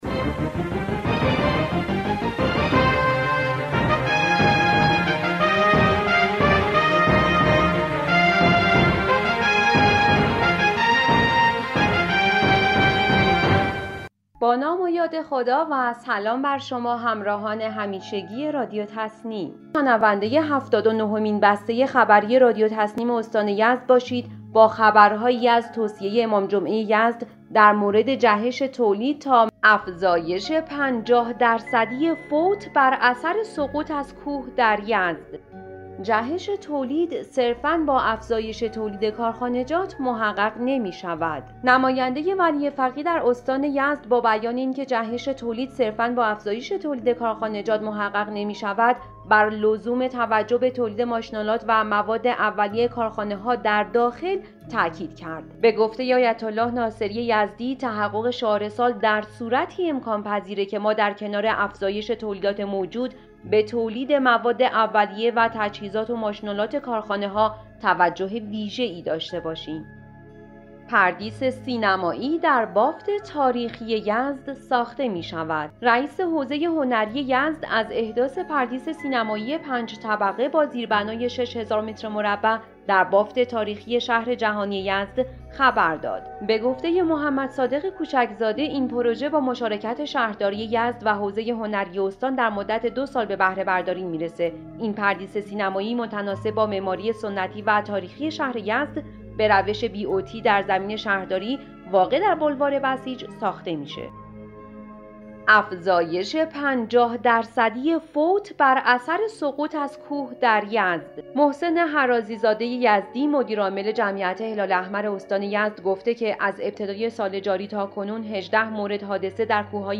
به گزارش خبرگزاری تسنیم از یزد, هفتاد و نهمین بسته خبری رادیو تسنیم استان یزد با خبرهایی از توصیه امام جمعه یزد در مورد جهش تولید, ساخت پردیس سینمایی در بافت تاریخی یزد, افزایش 50 درصدی فوت بر اثر سقوط از کوه و حمایت دستگاه قضا از صنعتگران و تولیدکنندگان راستین و خدمتگزار منتشر شد.